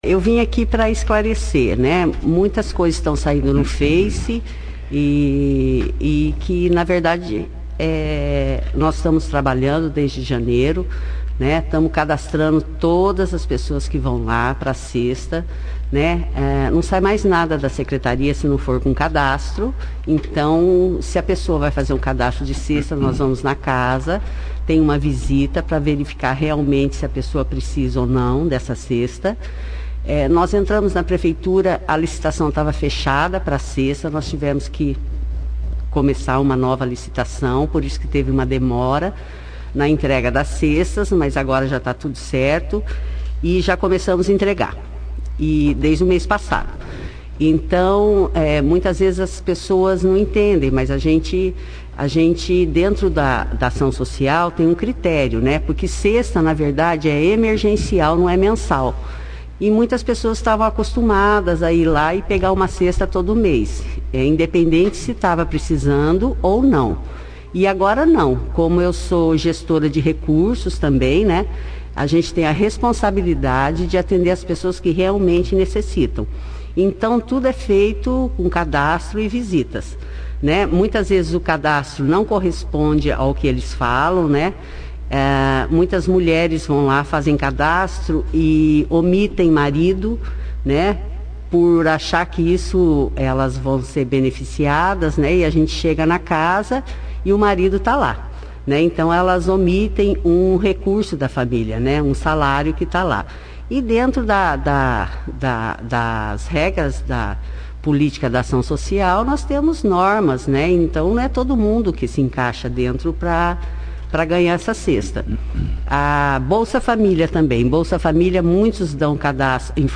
A Secretária de assistência social e assuntos da família de Bandeirantes, Mônica Zanardo de Sordi (foto), participou da 2ª edição do jornal Operação Cidade desta segunda-feira, 29/03, esclarecendo como esta sendo o trabalho desenvolvido pela assistência social principalmente neste momento de pandemia, quanto a distribuição de cestas básicas, funcionamento da cozinha comunitária, cadastramento, dentre outros detalhes de programas sociais desenvolvido pela secretaria.